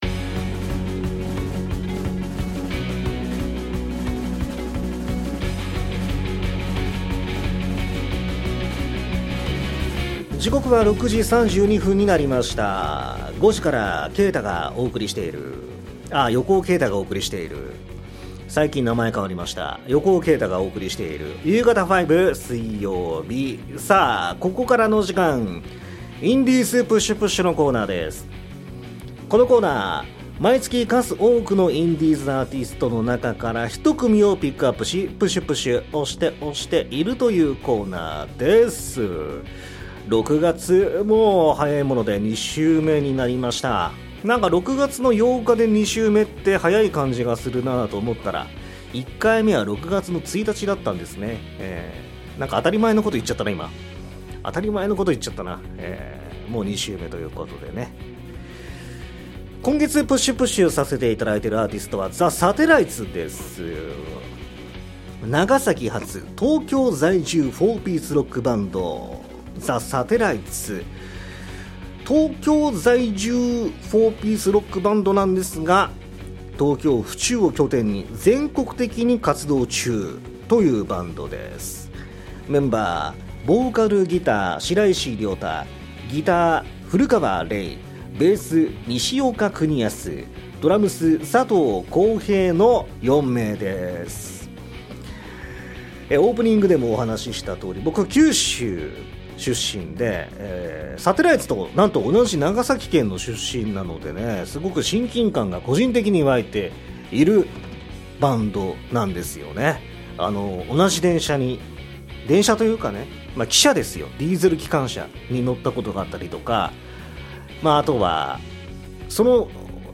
＜放送同録＞